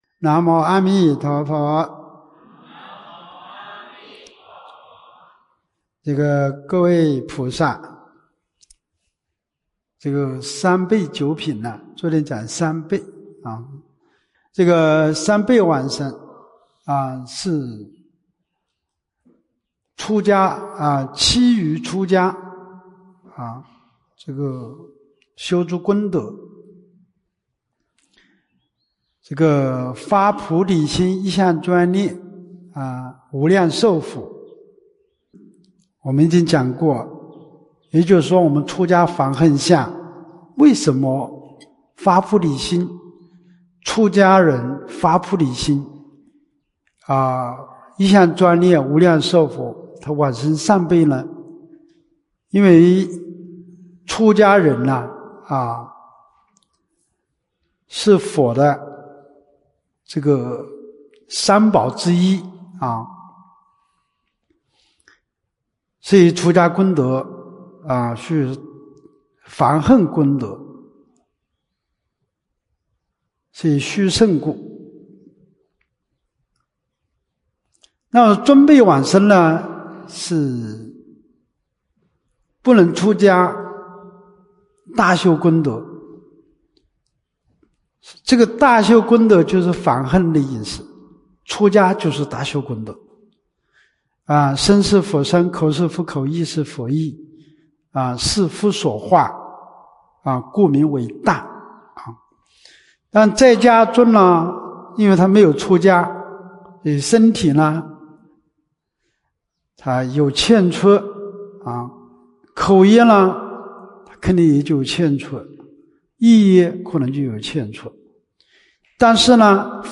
24年陆丰学佛苑冬季佛七（五）